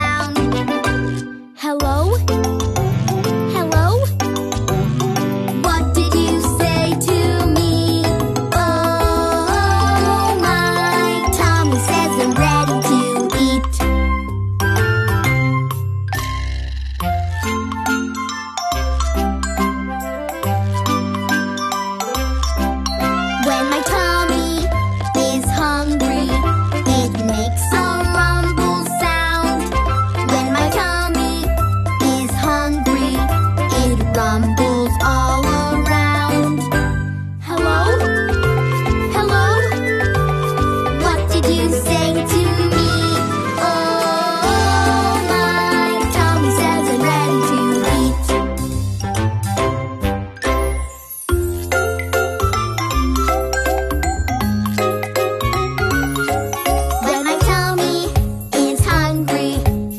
its making a strange growling sound